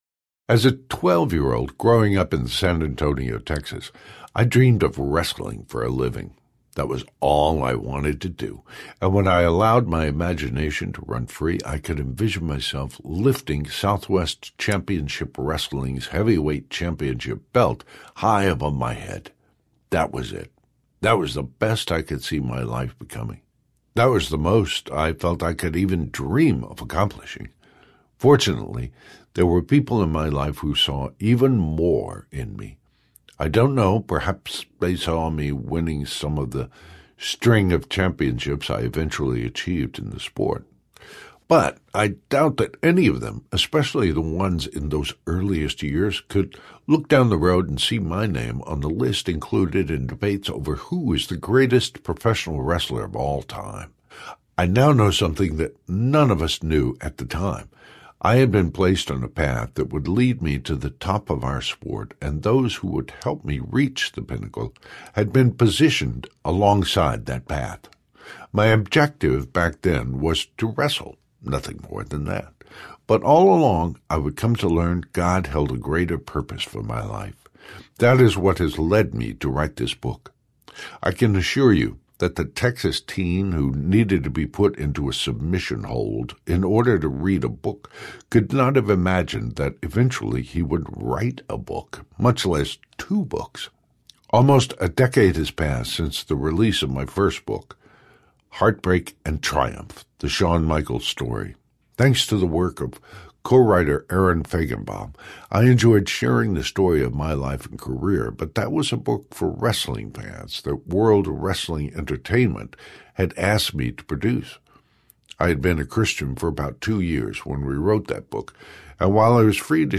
Wrestling for My Life Audiobook
5.8 Hrs. – Unabridged